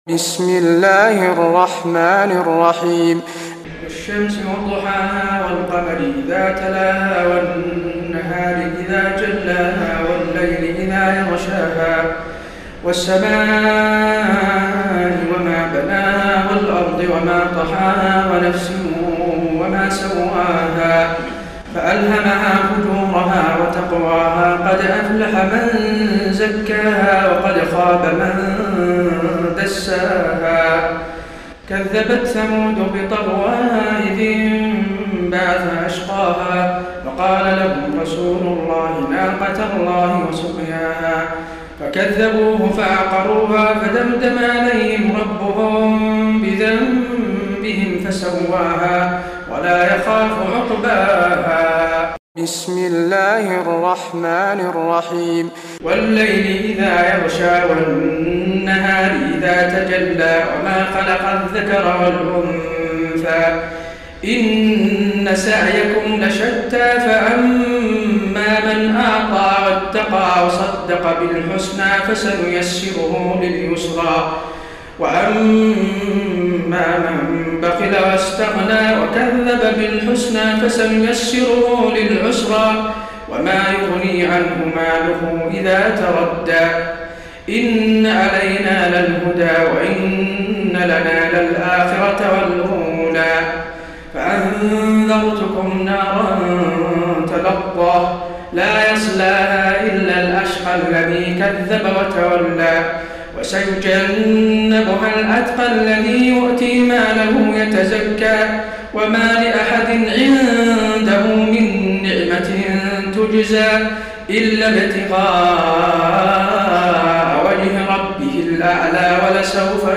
تراويح ليلة 29 رمضان 1432هـ من سورة الشمس الى الناس Taraweeh 29 st night Ramadan 1432H from Surah Ash-Shams to An-Naas > تراويح الحرم النبوي عام 1432 🕌 > التراويح - تلاوات الحرمين